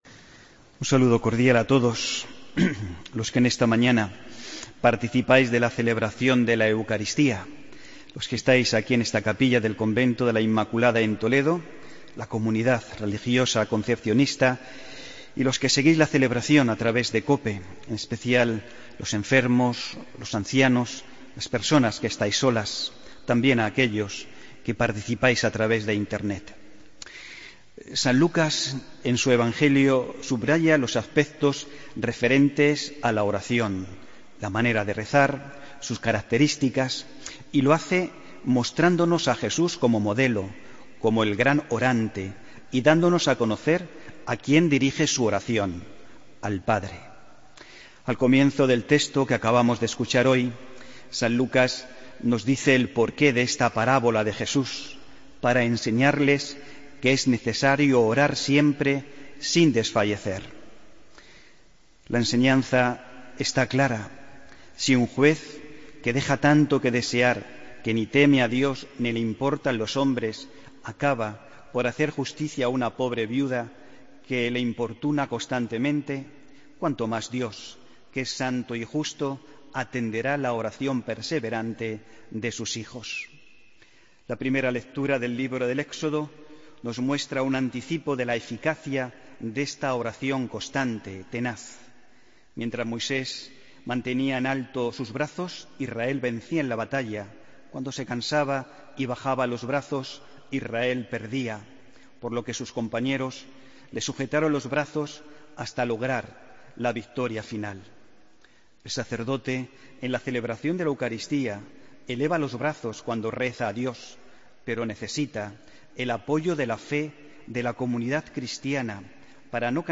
Homilía del domingo 16 de octubre de 2016